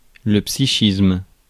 Ääntäminen
Synonyymit psyché Ääntäminen France Tuntematon aksentti: IPA: [psiʃism̭] IPA: /psi.ʃism/ Haettu sana löytyi näillä lähdekielillä: ranska Käännös Substantiivit 1. психика Suku: m .